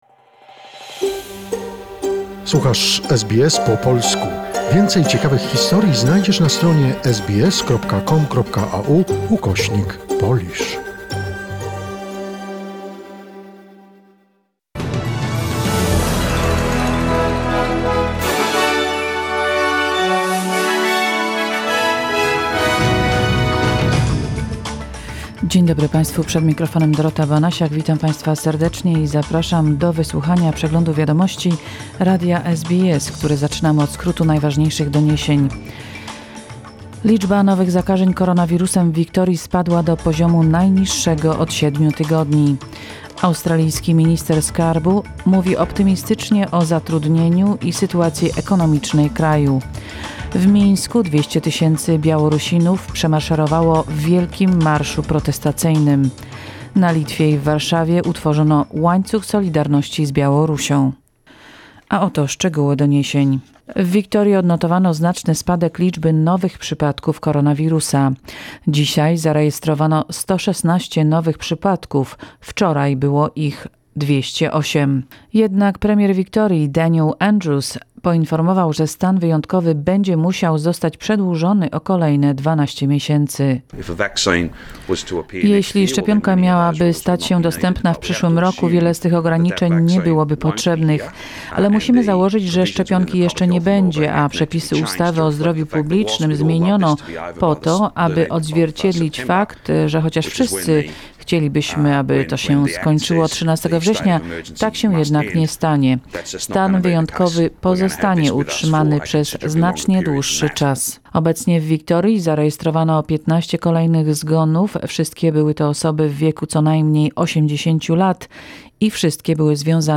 SBS News, 24 August 2020